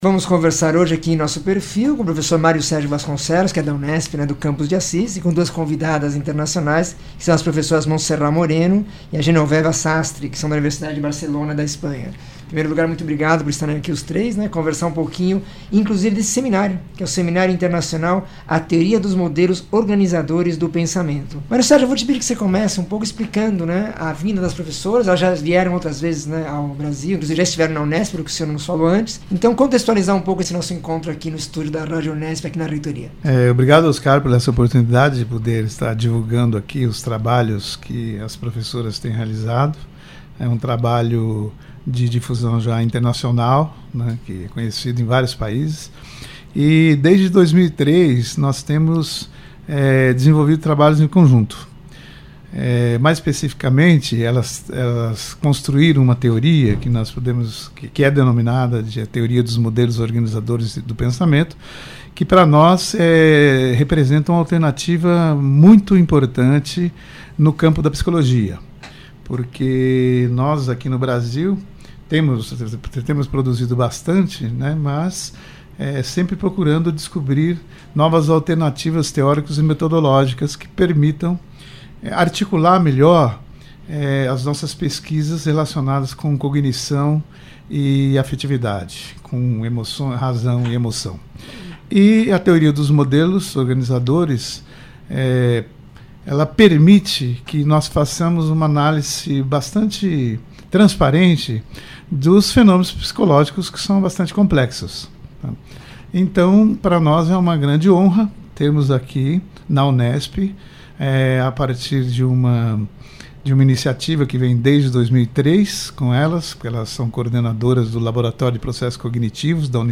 Reúne entrevistas com escritores e profissionais das mais diversas áreas que falam de suas criações e pesquisas.